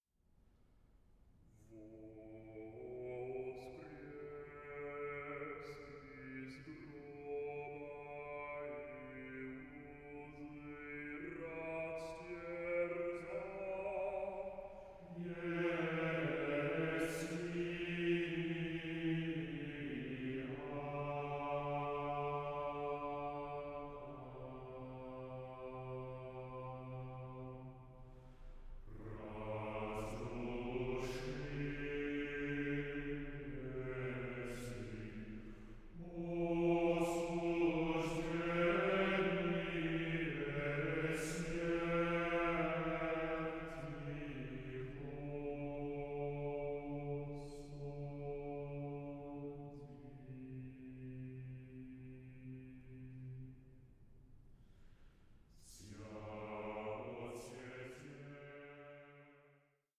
choral masterpiece